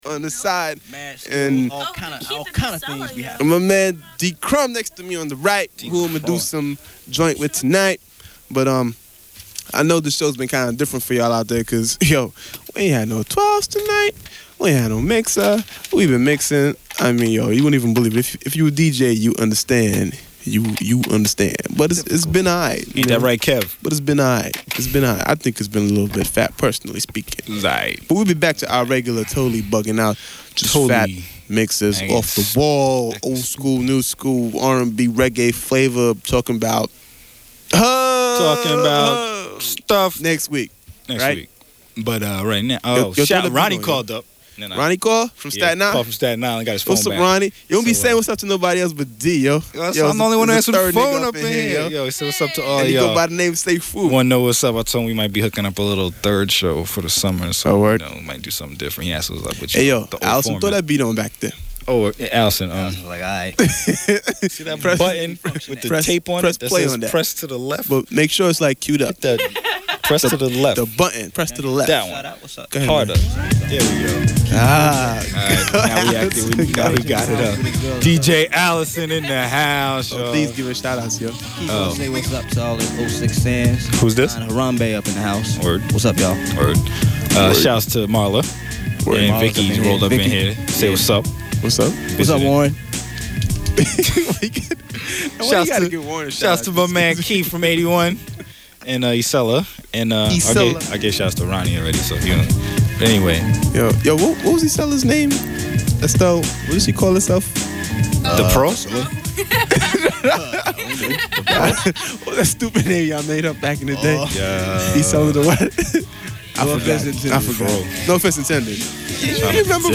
Show – May 20th, 1993 This is one of the earlier shows after the jump to Thursdays and into the old Raw Deal slot.
In a nutshell, we had no turntables that night so we tried to mix off the board equipment (not real fresh). So we ended up doing just about anything to fill the 3 hours, poetry, freestyles, lots of shout outs…and of course pure buggin’.